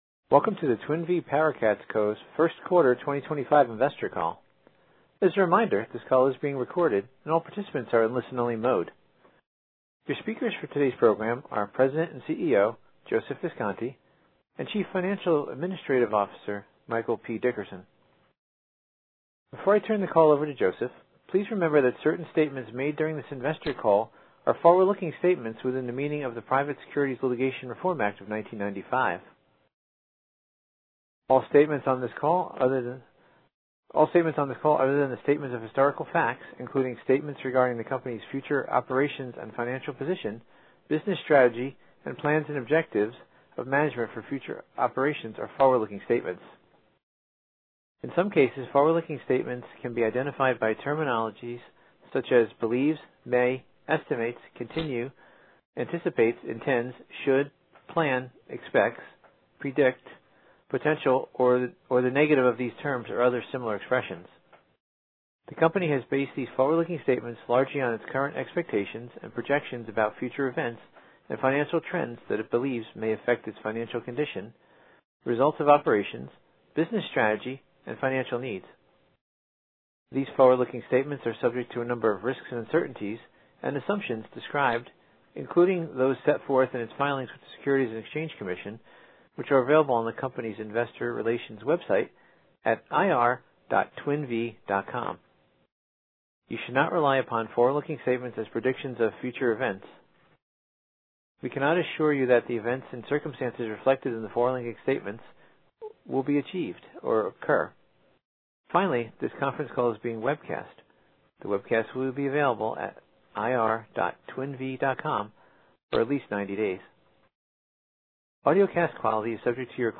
Twin Vee PowerCats Co. First Quarter 2025 Financial Results Conference Call